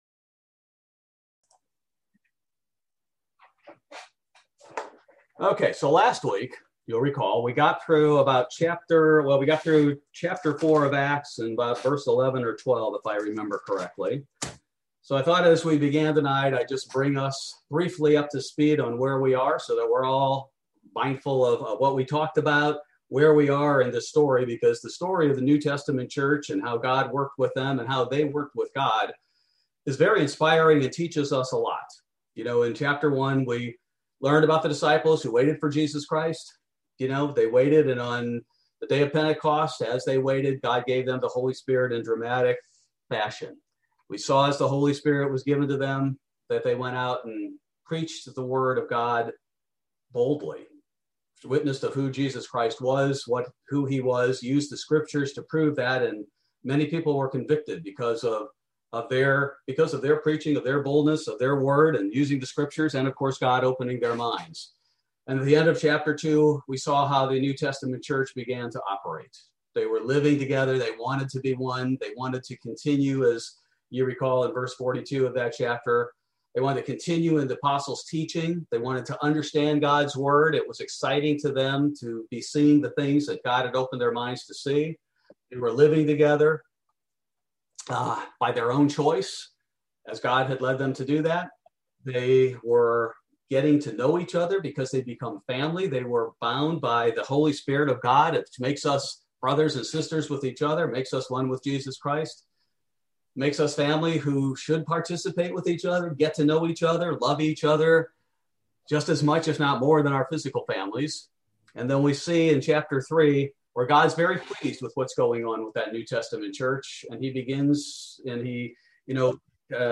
Bible Study: June 2, 2021